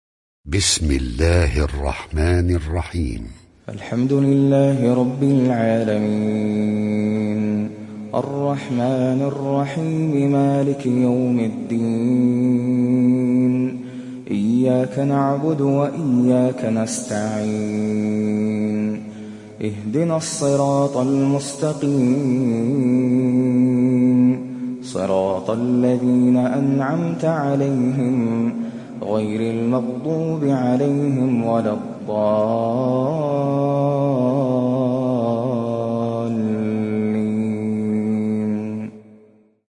دانلود سوره الفاتحه mp3 ناصر القطامي روایت حفص از عاصم, قرآن را دانلود کنید و گوش کن mp3 ، لینک مستقیم کامل